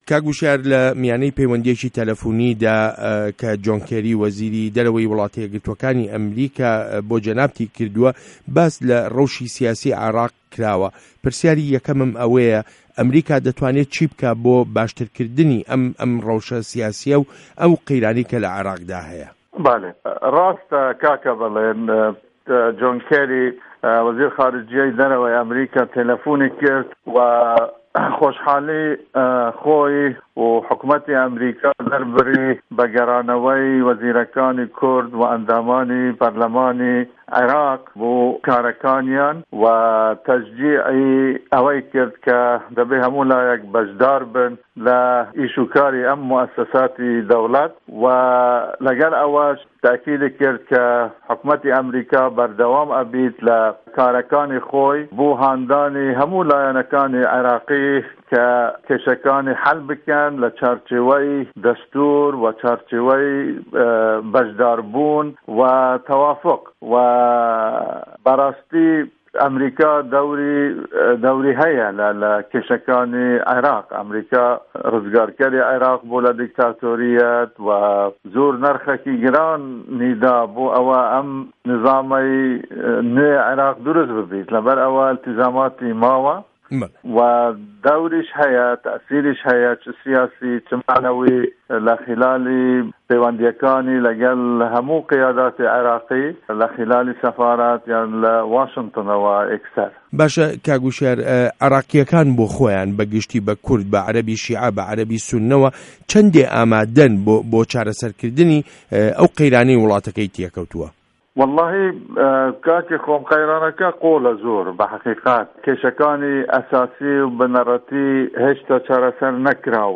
Hevpeyvîn bi birêz Hişyar Zêbarî re